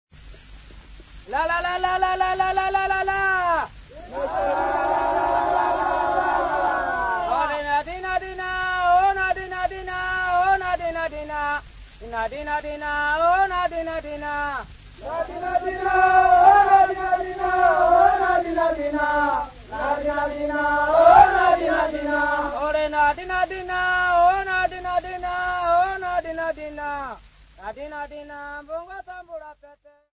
Zande elephant drivers "cornacs"
Folk Music
Field recordings
Africa Democratic Republic of Congo City not specified f-cg
Indigenous music